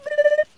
Bruuuu Sound Buttons